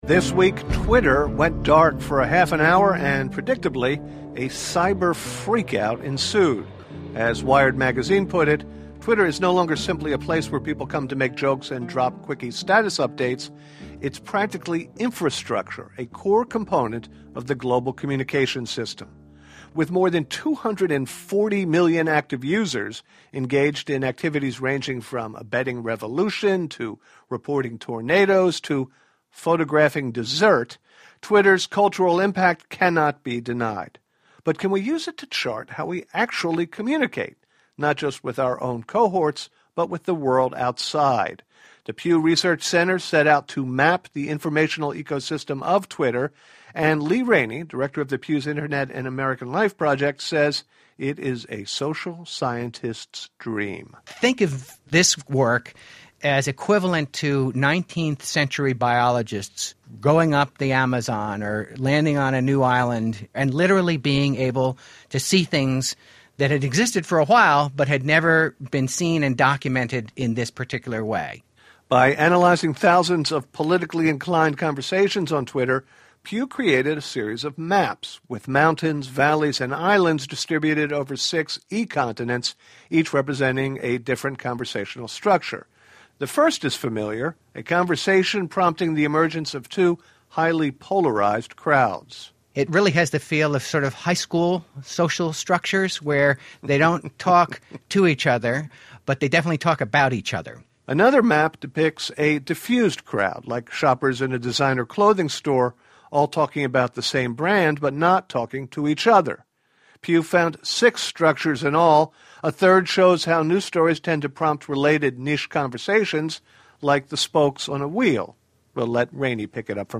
Lee Rainie, director of the Pew Internet Research Center was interviewed by Bob Garfield on OnTheMedia this week about the recently released report on mapping Twitter topic networks. The report found six distinct patterns of social media networks in Twitter: divided, unified, fragmented, clustered, and in and out hub and spoke patterns. They discuss the prospects for overcoming polarization in social media and the hopeful signs that many other forms of social network structures exist in addition to the divided network pattern.